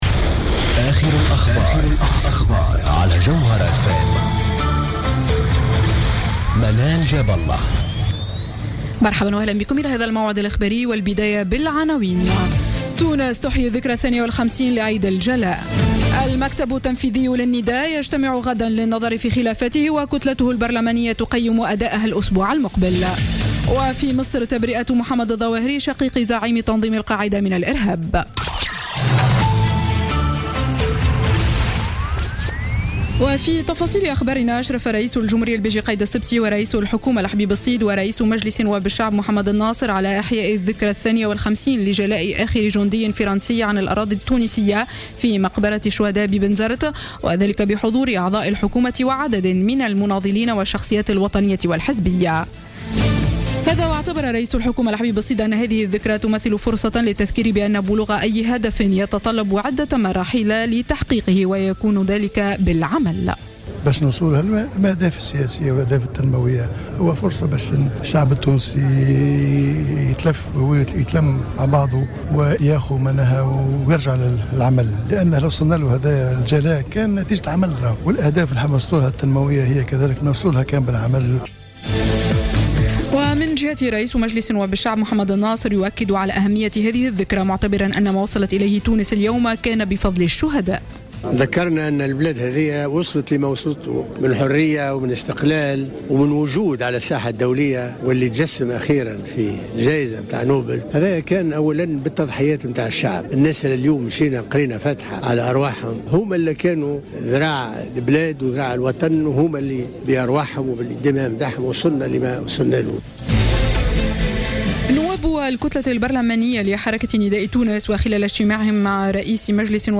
نشرة أخبار السابعة مساء ليوم الخميس 15 أكتوبر 2015